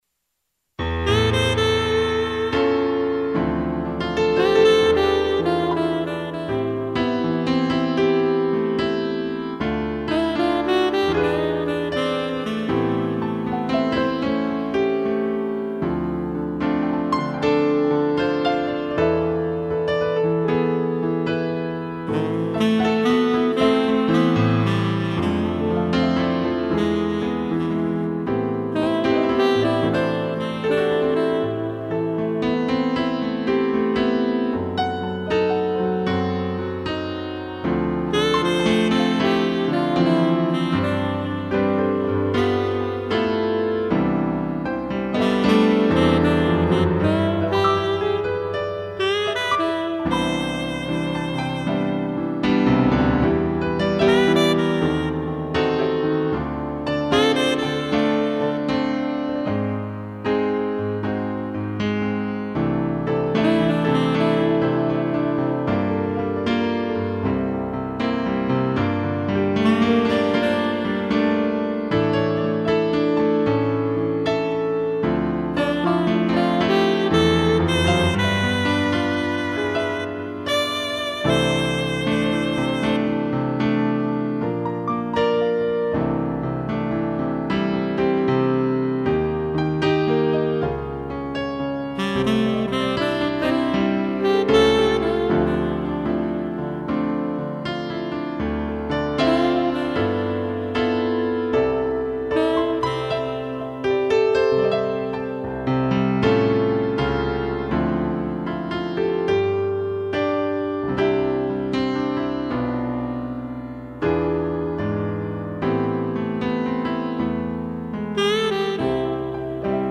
2 pianos e sax
instrumental